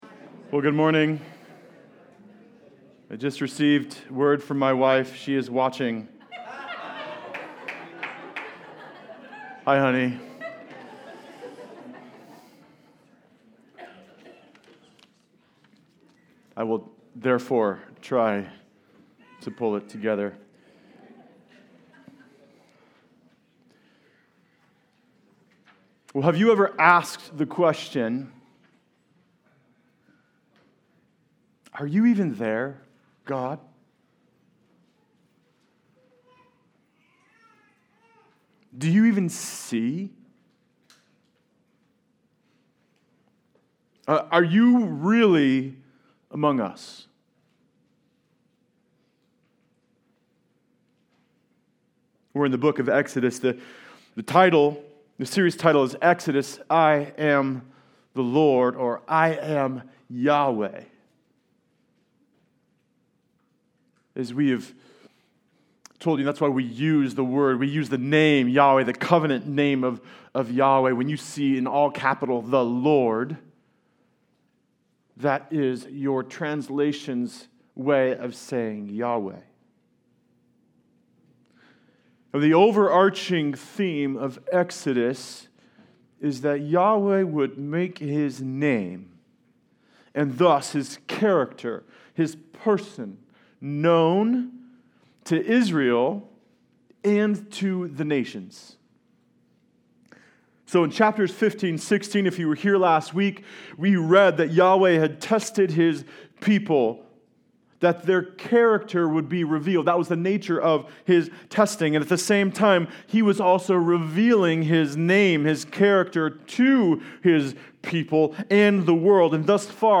Passage: Exodus 17:1-7 Service Type: Sunday Service